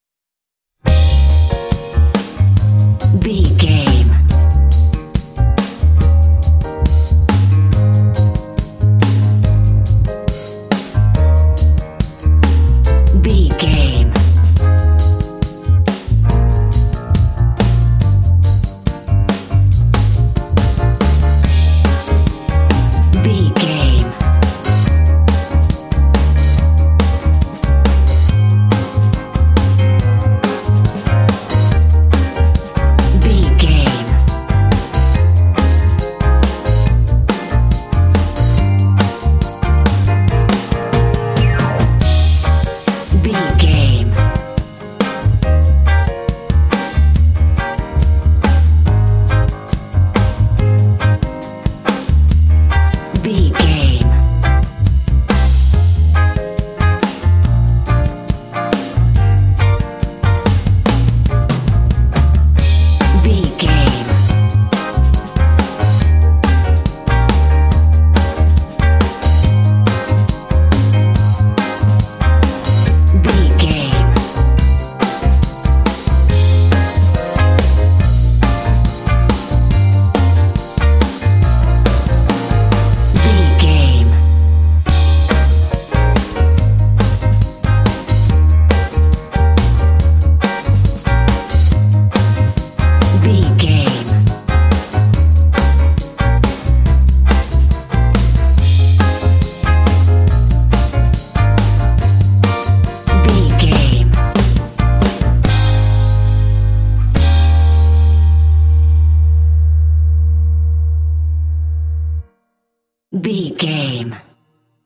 Aeolian/Minor
E♭
funky
groovy
bright
piano
drums
electric guitar
bass guitar
blues
jazz